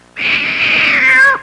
Cat Squeal Sound Effect
Download a high-quality cat squeal sound effect.
cat-squeal.mp3